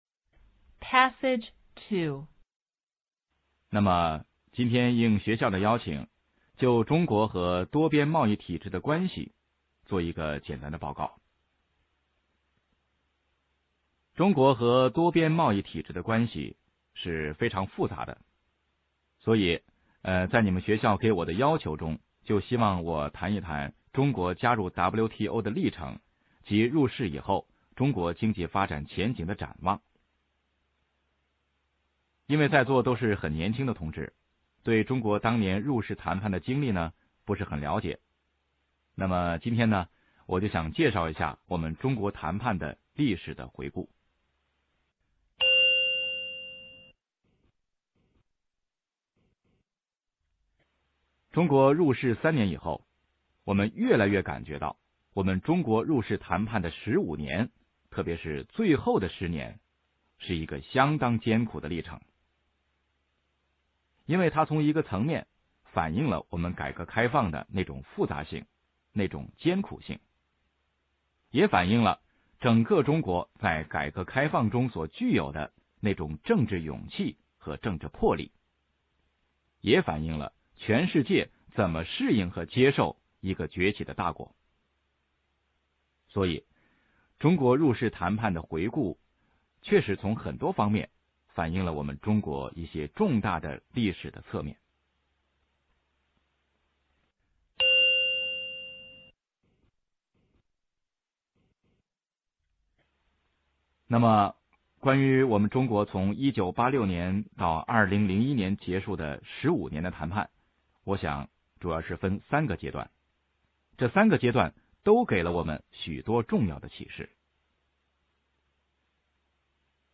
MP3音频下载_翻译硕士MTI《商务口译》教材(MP3+文本)Unit17-2:关于中国和多边贸易体制的关系的演讲(上)_可可英语